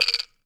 wood_block_rattle_movement_04.wav